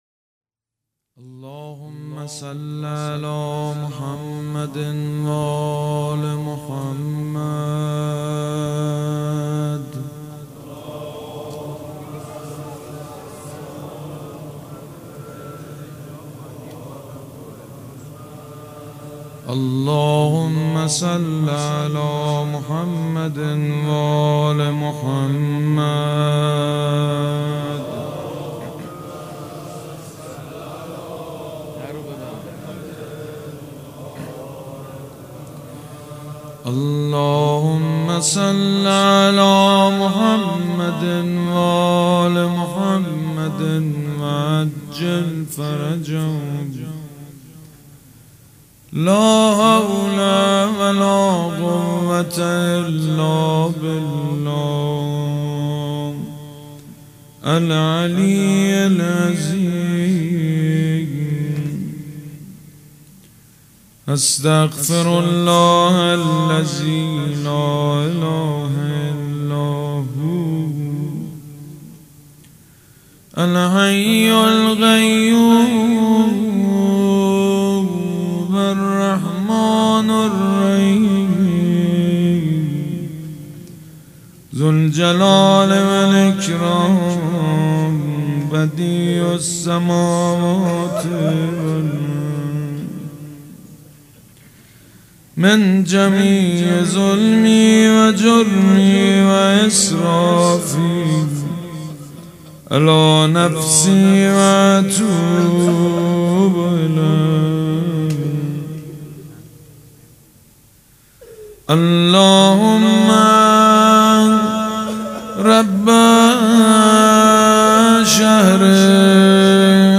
مراسم مناجات شب بیست و چهارم ماه رمضان
حسینیه ریحانة‌الحسین(س)
مناجات